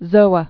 (zōə)